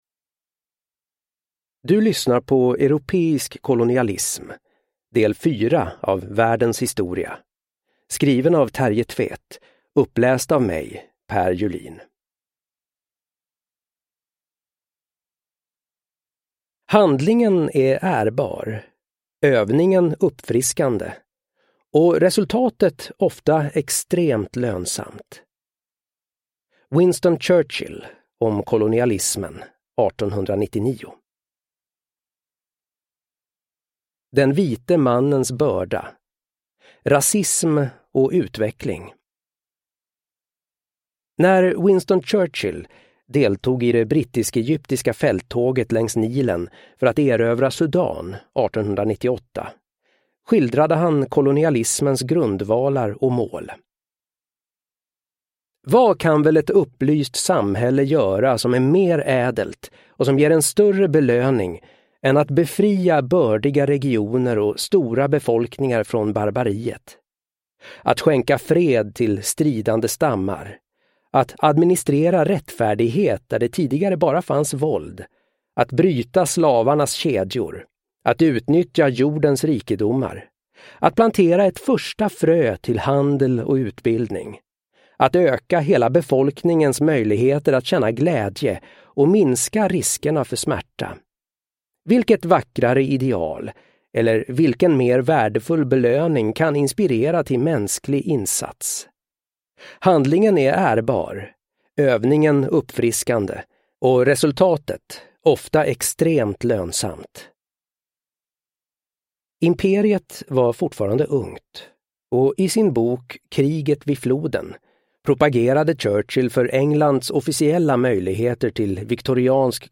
Världens historia: Del 4 – Europeisk kolonialism – Ljudbok – Laddas ner